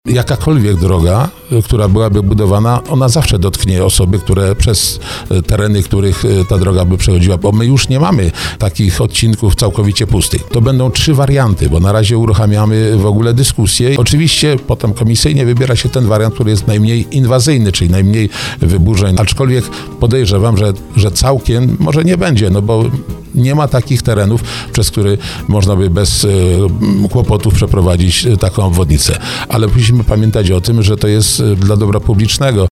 Nie ma już terenów, przez które moglibyśmy poprowadzić drogę bez wpływu na budynki i prywatne działki – mówił w porannym programie Słowo za Słowo wicemarszałek małopolski.